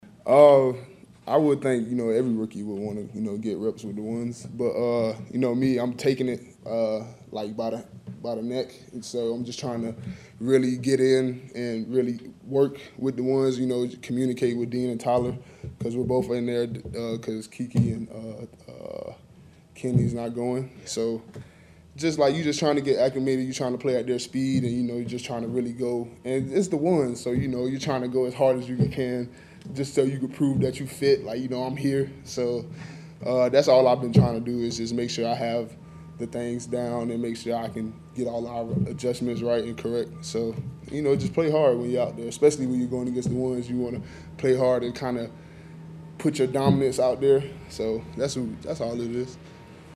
After practice, Slaton talked about running with the 1’s: